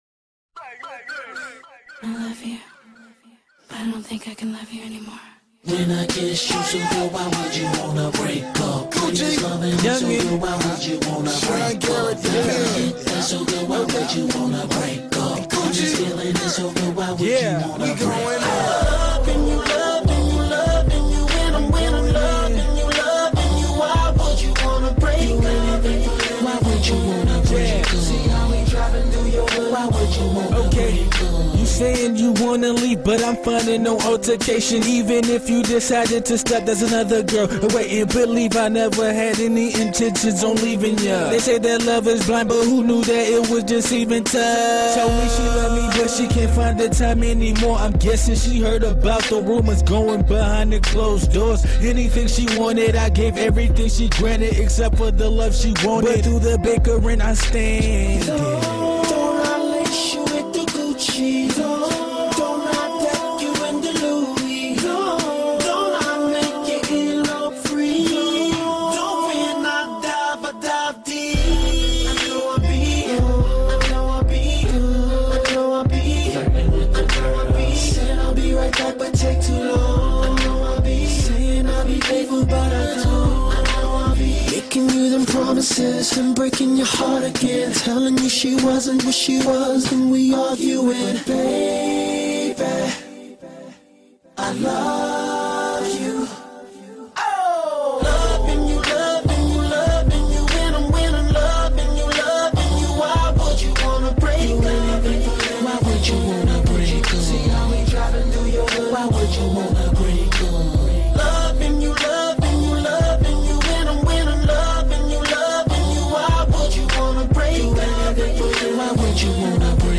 Category: HipHop Music